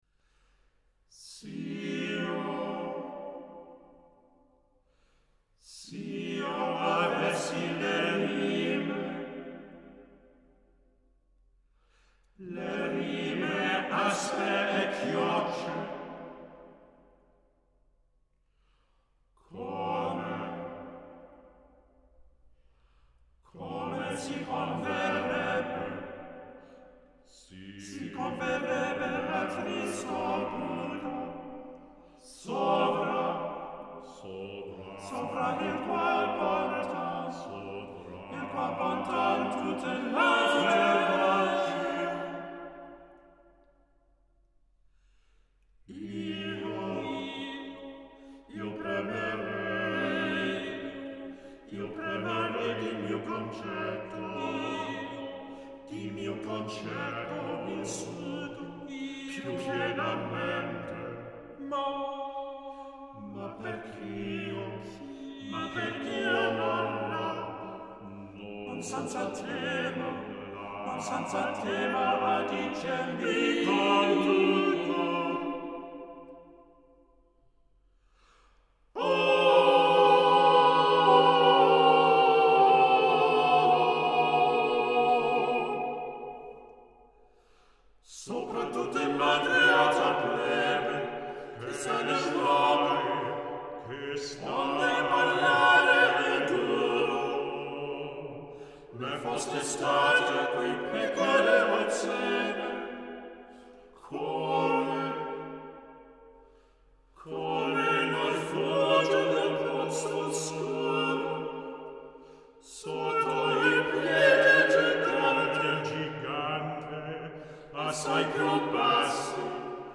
One of the world’s finest vocal chamber groups
at the St Gerold monastery in Austria